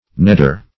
nedder - definition of nedder - synonyms, pronunciation, spelling from Free Dictionary Search Result for " nedder" : The Collaborative International Dictionary of English v.0.48: Nedder \Ned"der\, n. [See Adder .]